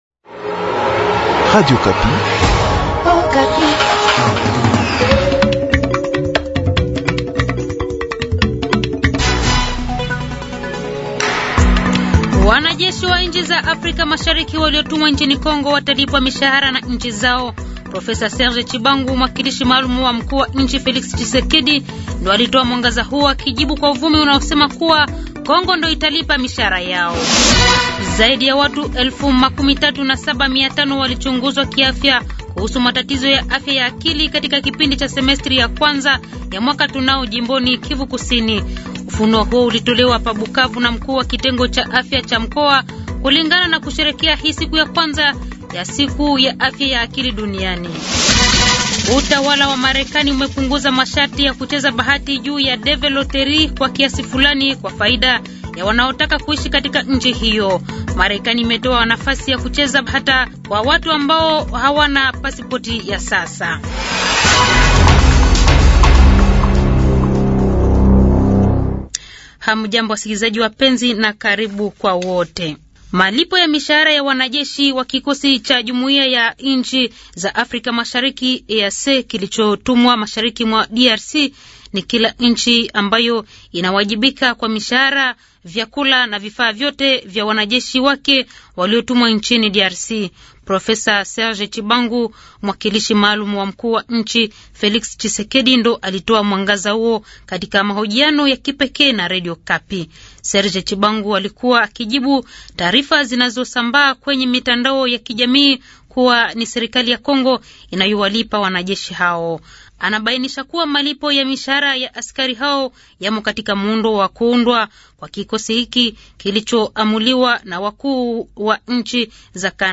Journal matin
Habari za siku ya kwanza asubuhi